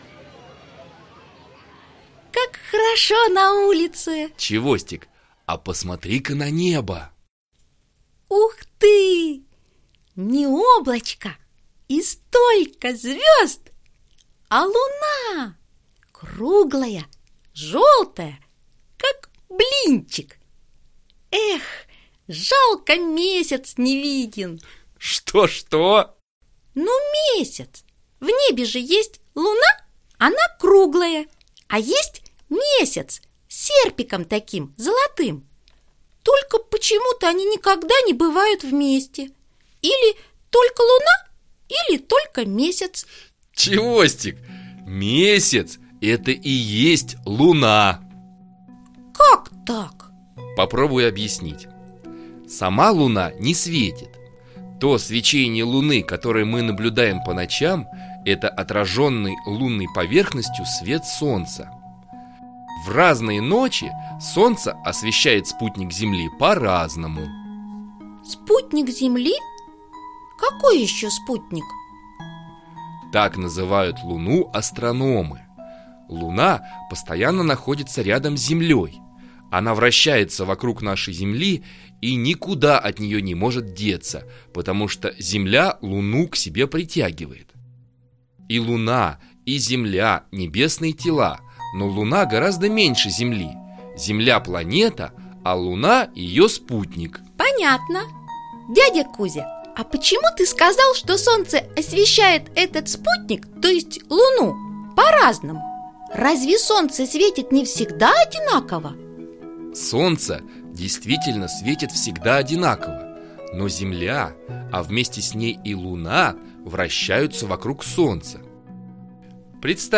Аудиокнига Увлекательная астрономия | Библиотека аудиокниг